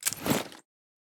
armor-close-2.ogg